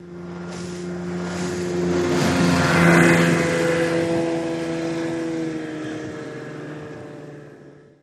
20 hp Johnson Boat Pass By, Fast